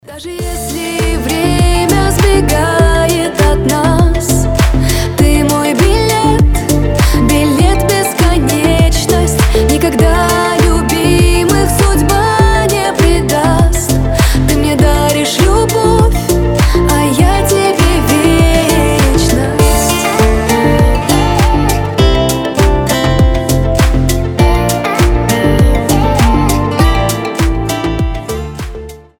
• Качество: 320, Stereo
красивые
душевные